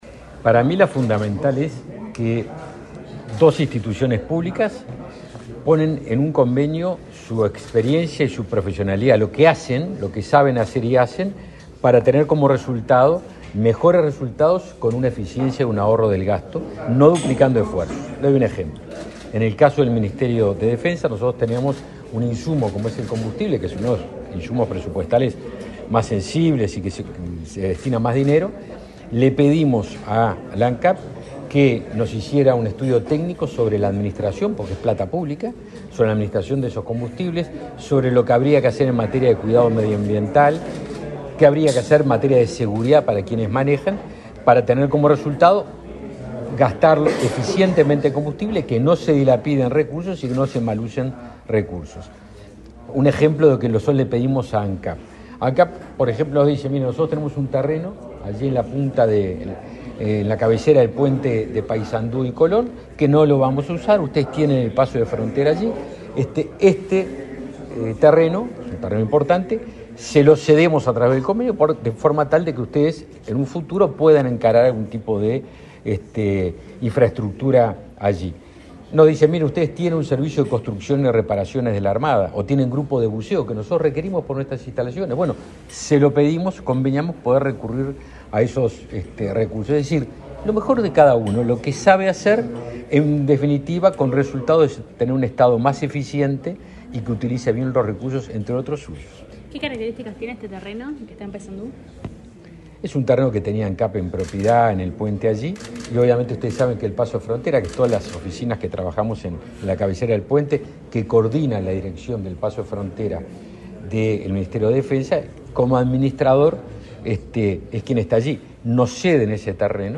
Declaraciones a la prensa del ministro de Defensa Nacional, Javier García
Declaraciones a la prensa del ministro de Defensa Nacional, Javier García 26/10/2022 Compartir Facebook X Copiar enlace WhatsApp LinkedIn Tras participar en el acto por la firma de convenio entre el Ministerio de Defensa Nacional y Ancap para realizar acciones conjuntas, este 26 de octubre, el ministro García realizó declaraciones a la prensa.